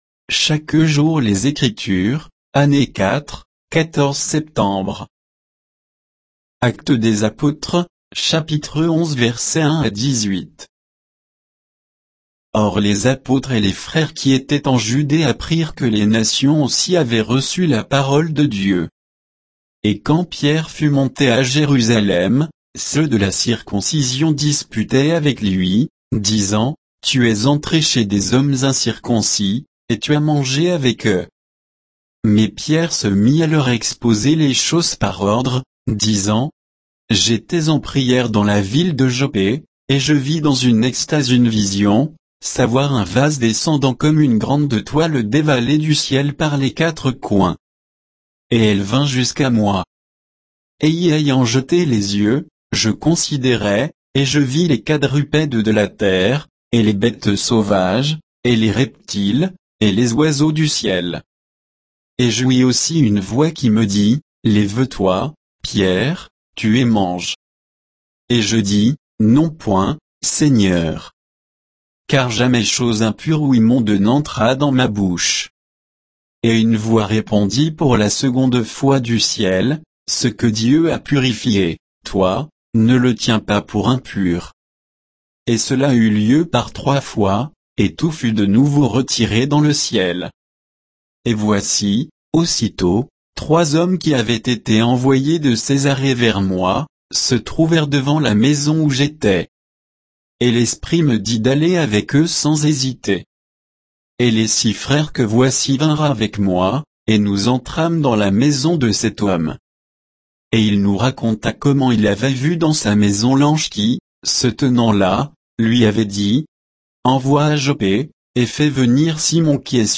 Méditation quoditienne de Chaque jour les Écritures sur Actes 11